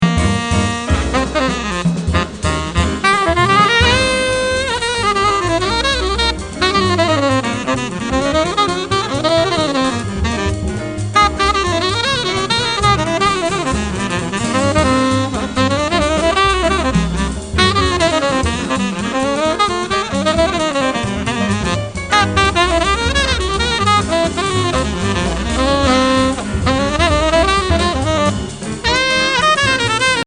The Best In British Jazz